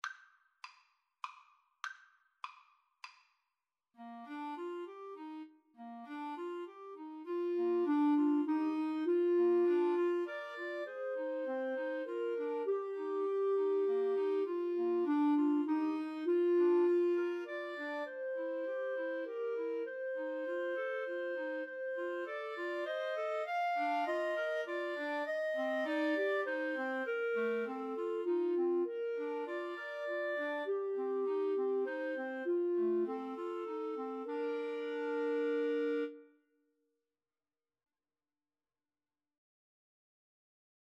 Bb major (Sounding Pitch) C major (Clarinet in Bb) (View more Bb major Music for Clarinet Trio )
Moderato
3/4 (View more 3/4 Music)
Clarinet Trio  (View more Easy Clarinet Trio Music)